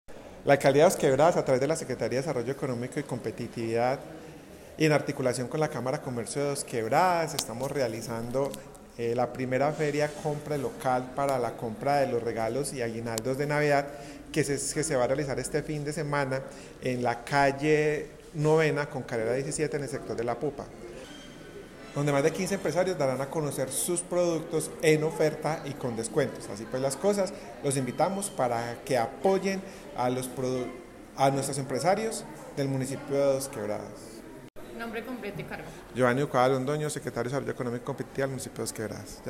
Comunicado_Audio_secretario_Desarrollo_Econo_mico_y_Competitividad_Geovanny_Ducuara.mp3